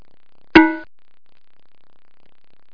1 channel
cowbell.mp3